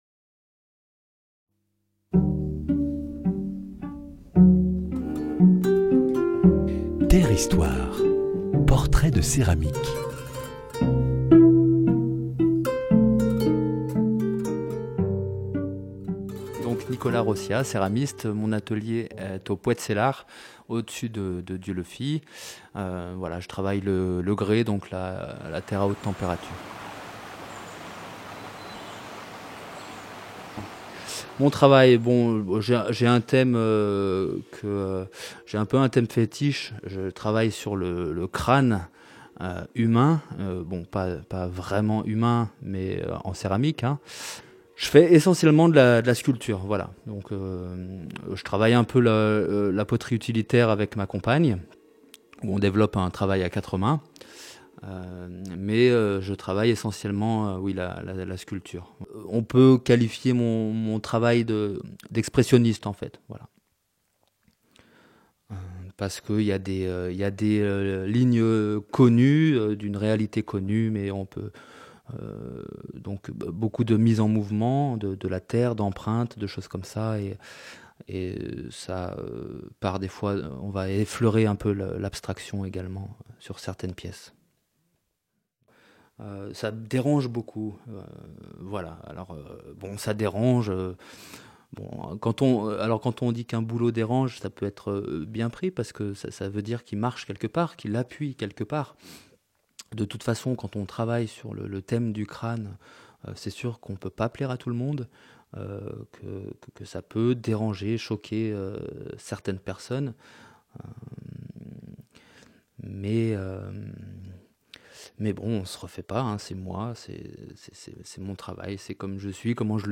Dans le cadre du 14e marché des potiers de Dieulefit, portraits de céramiques en atelier: matériaux, gestes créatifs, lien avec la matière, 8 potiers évoquent la terre de leur quotidien….